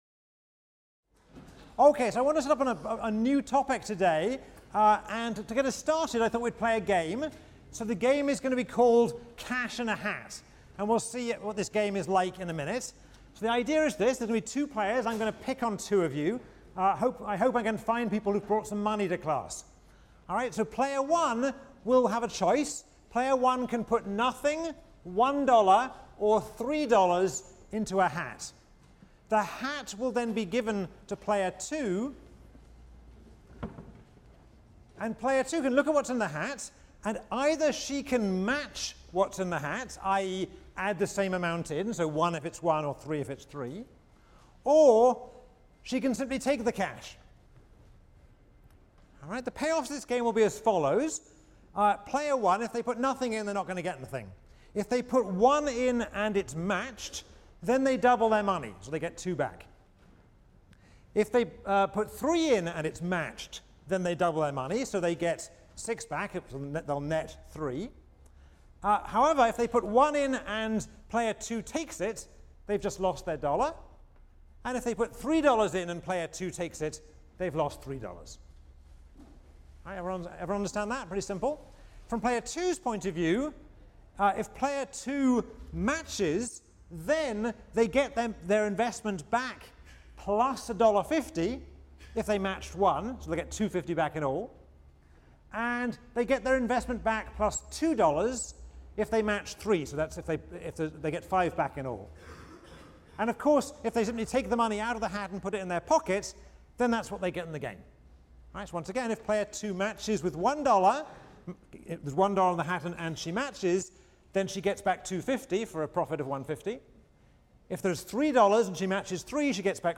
ECON 159 - Lecture 13 - Sequential Games: Moral Hazard, Incentives, and Hungry Lions | Open Yale Courses